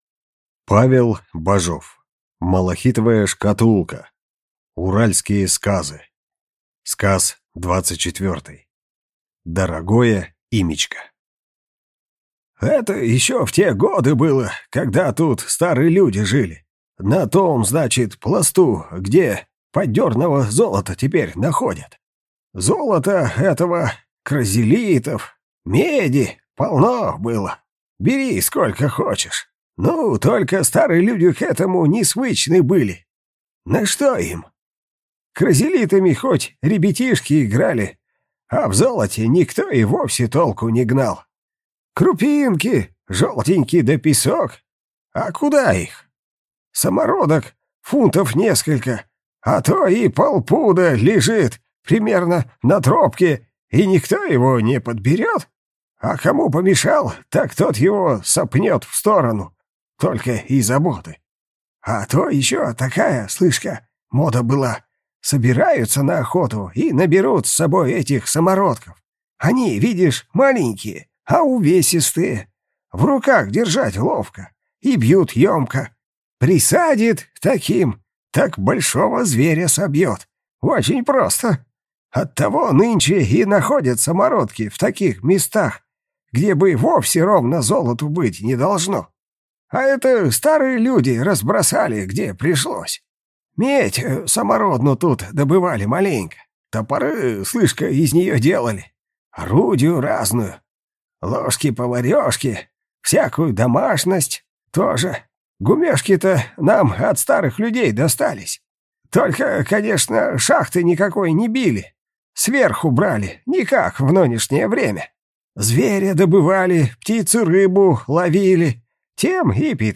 Аудиокнига Дорогое имячко | Библиотека аудиокниг